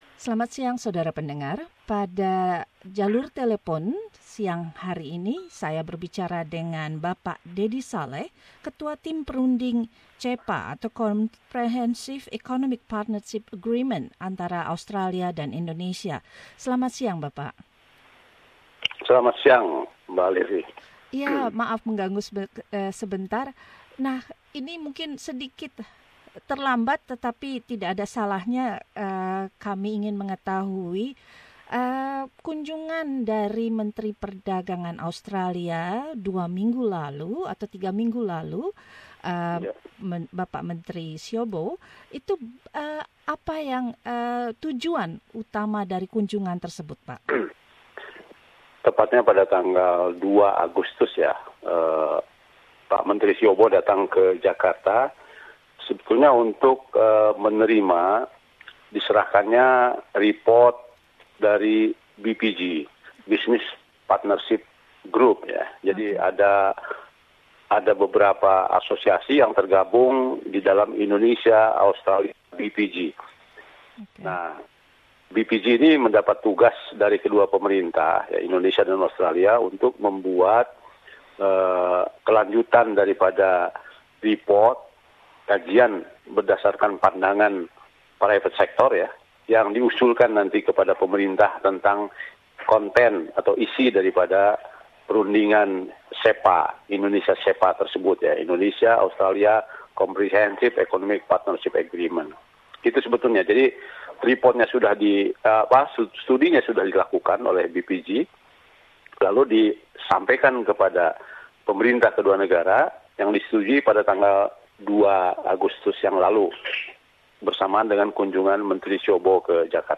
Ketua tim negosiator dari Kementrian Perdagangan Indonesia, Bapak Deddy Saleh menjelaskan kemajuan dari perjanjian dalam wawancara ini.